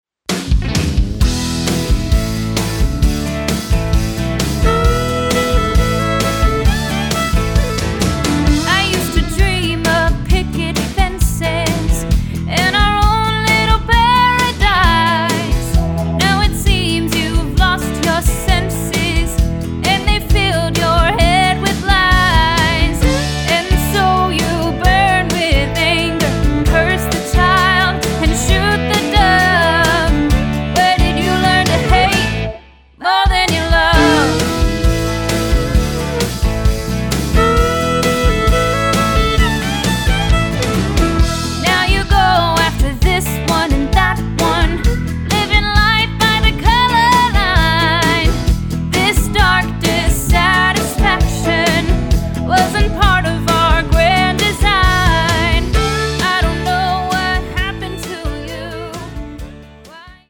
a wonderful young singer
pointed rockers